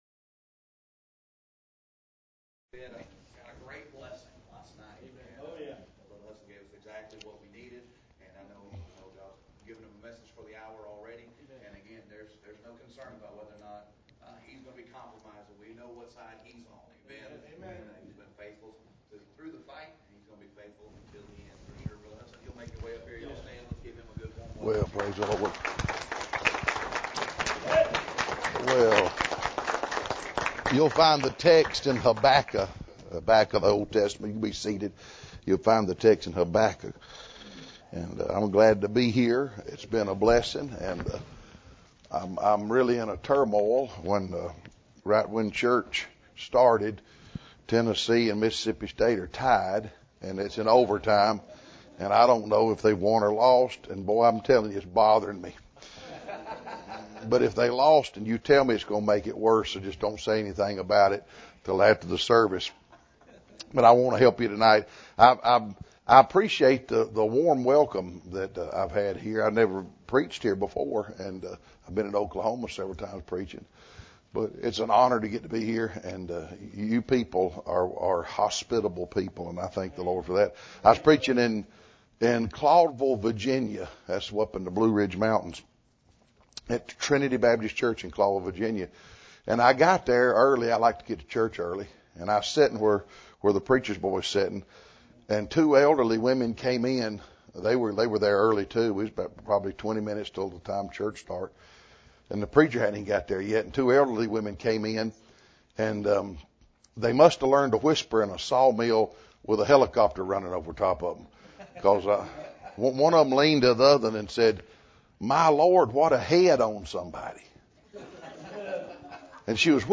This sermon is not a sentimental escape from reality. It is a call to discover how to find spiritual rest amid judgment, trial, and weariness—rest that comes from God, anchored in His word, His promises, and the Person of Christ.